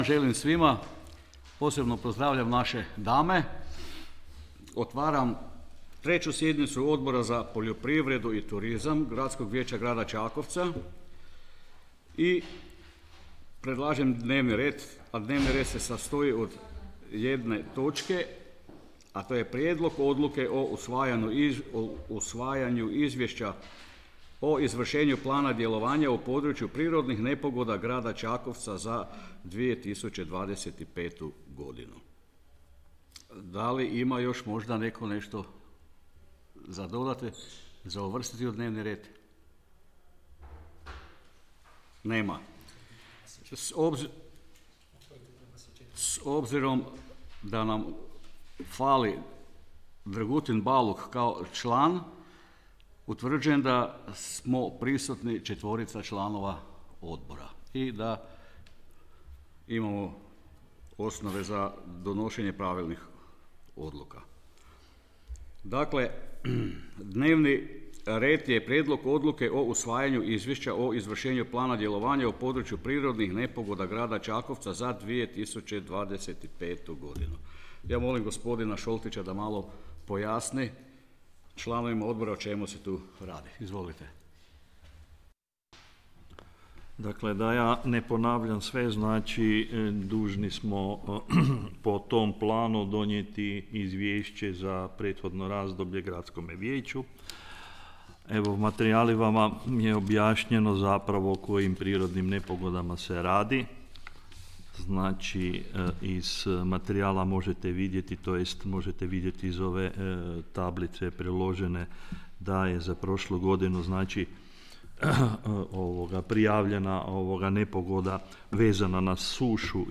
Obavještavam Vas da će se 3. sjednica Odbora za poljoprivredu i turizam Gradskog vijeća Grada Čakovca održati 23. veljače 2026. godine (ponedjeljak) u 11:00 sati, u prostorijama Uprave Grada Čakovca, Ulica kralja Tomislava 15, Čakovec.